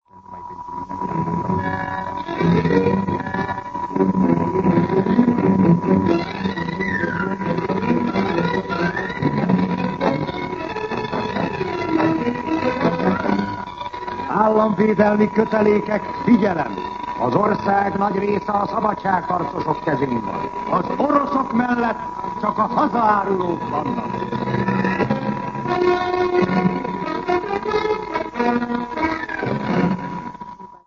Jelmondatok 1956. október 27. 19:48 ● 00:30 ► Meghallgatom Műsor letöltése MP3 00:00 00:00 A műsor leirata Szignál Államvédelmi kötelékek, figyelem! Az ország nagy része a szabadságharcosok kezén van!